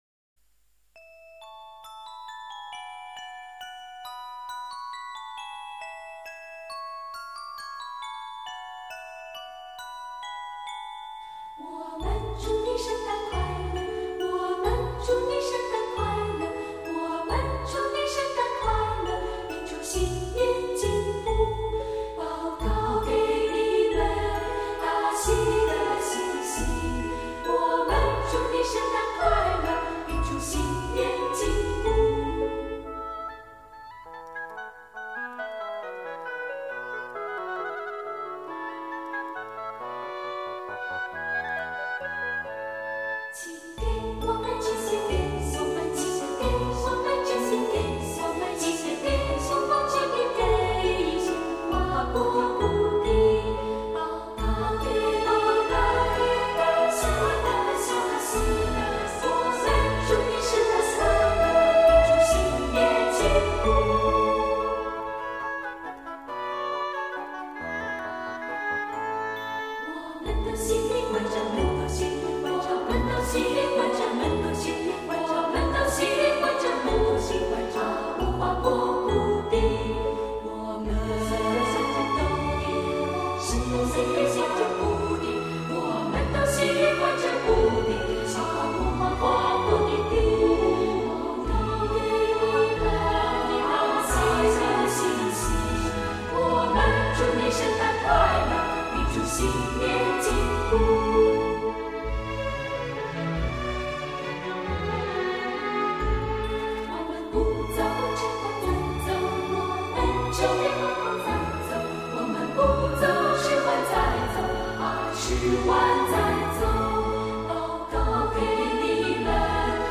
听！那交替的复调、淡雅的和声，不正是我们梦中田园般许久未唱的深情牧歌吗……
一首首歌曲经她们清纯自然、质朴无华的演绎，使听者犹如酒至微酣，得到至纯至美的享受。